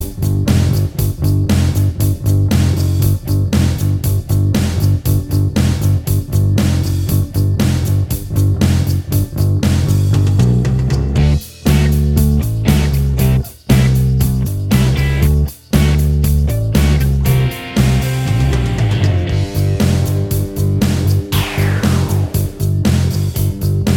Minus Guitars Pop (1990s) 3:10 Buy £1.50